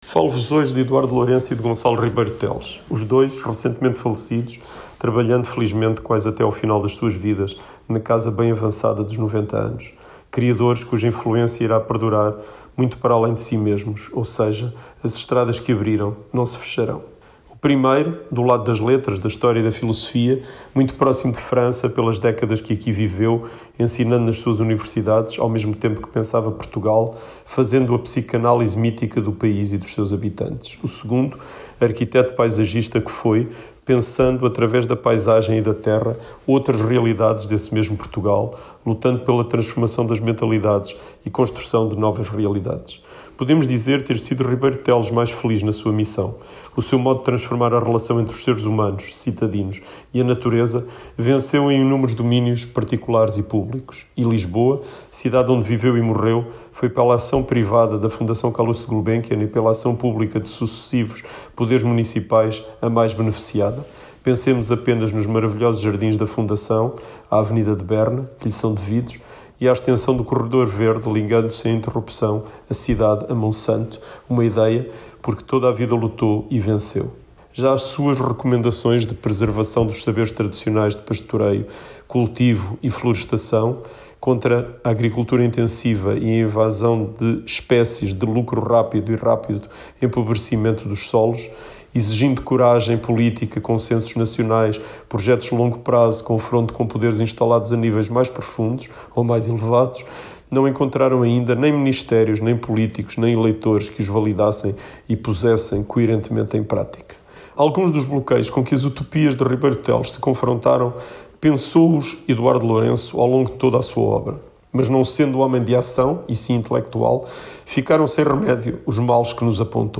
Ouça aqui a última crónica